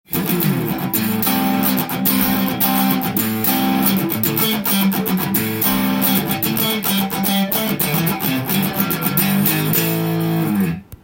このギターの特徴は、シングルコイルの細く綺麗な音で
癖がなく王道のジャキジャキサウンドです。
歪ませても細い中に芯のある音がする感じで、さすが日本製です！